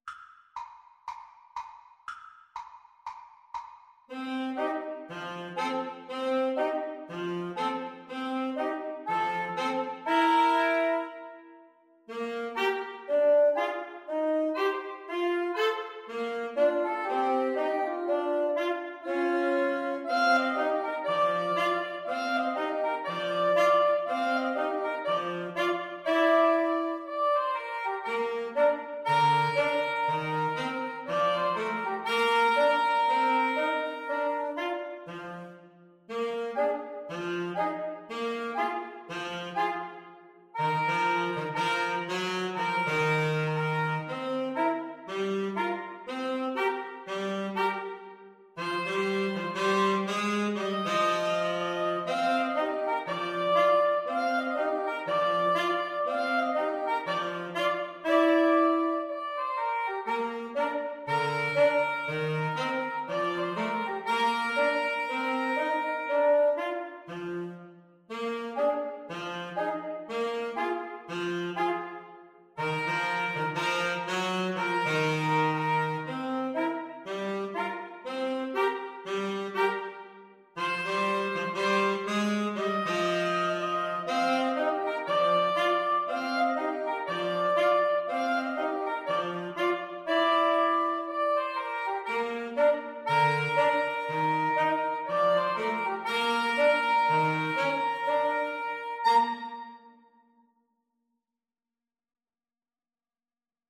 Allegro (View more music marked Allegro)
Woodwind Trio  (View more Intermediate Woodwind Trio Music)
Jazz (View more Jazz Woodwind Trio Music)